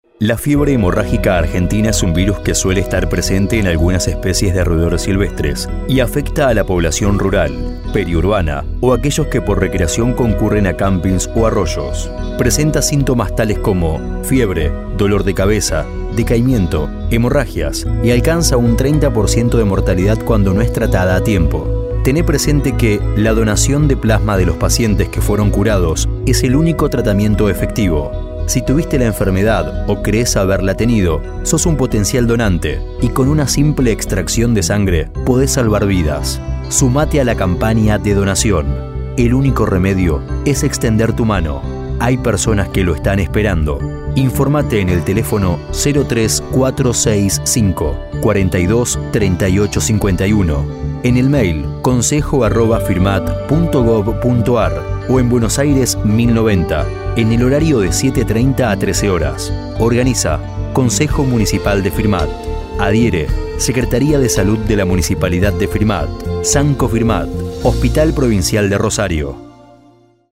CAMPAÑA-DONACION-Radial.mp3